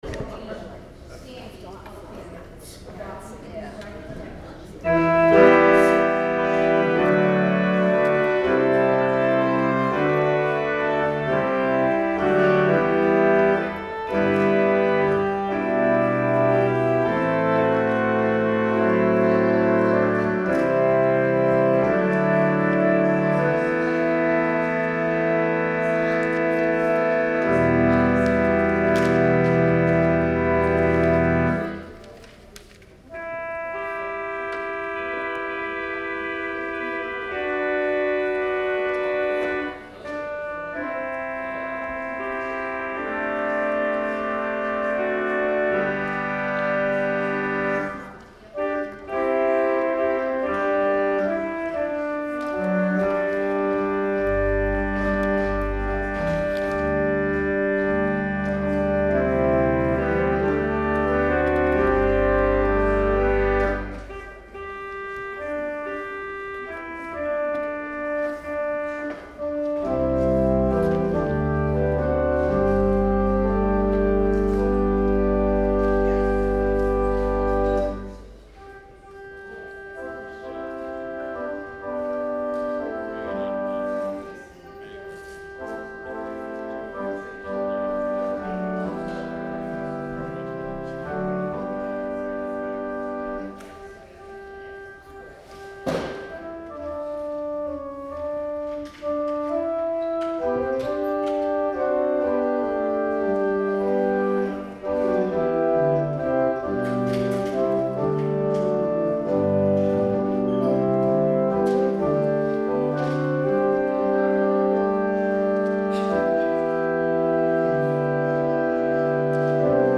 Music from December 8, 2019 Sunday Service
Prelude Improv